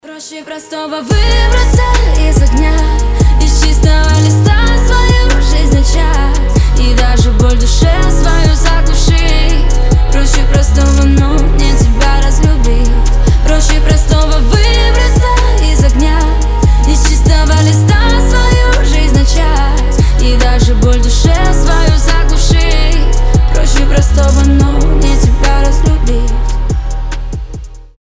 • Качество: 320, Stereo
красивые
лирика
грустные